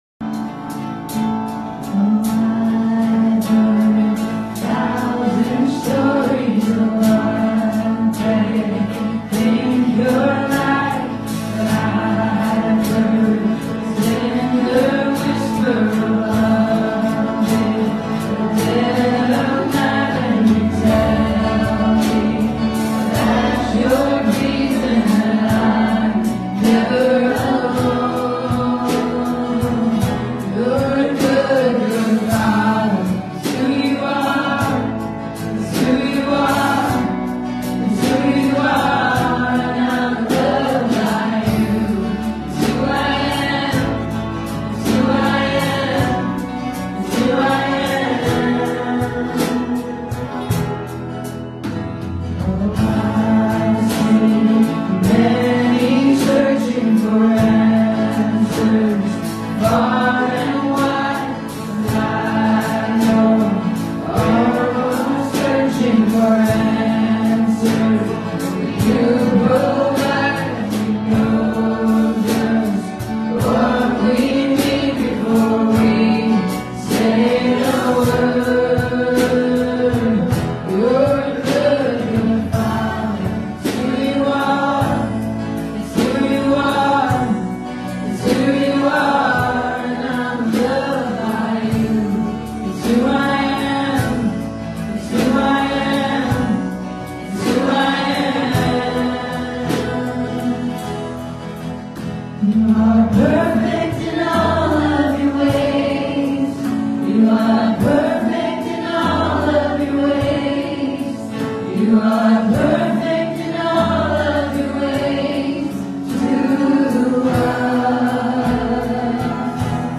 Romans Passage: Romans 6:19-23 Service Type: Sunday Morning « No Longer a Slave to Sin Faith